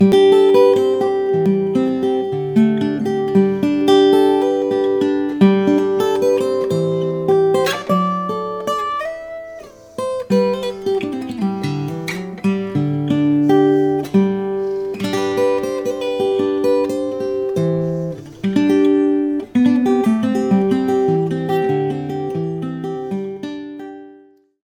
OO Spruce/Maple sunburst
This is #47, a "double O", a small guitar from woods that were orphans.
looks great and my oh my what a great sound!